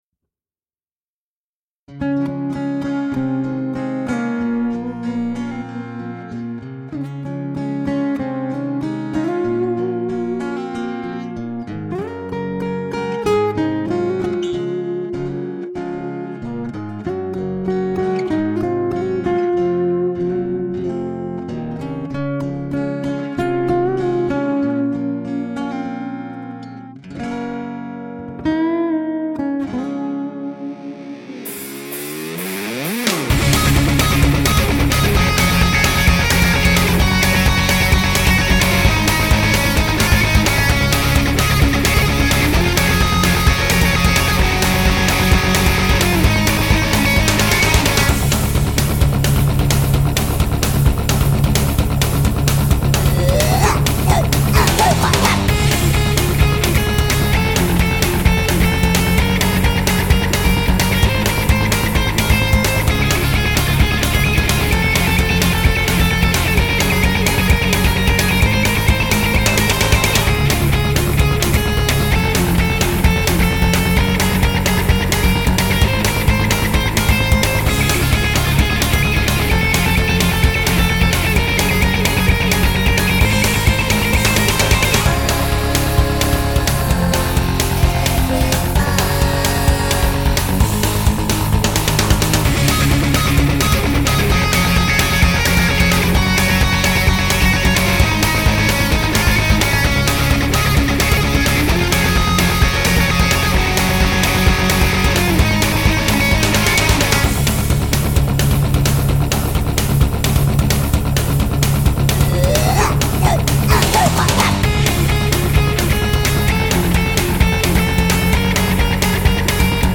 Ben du chant... y'en a pas !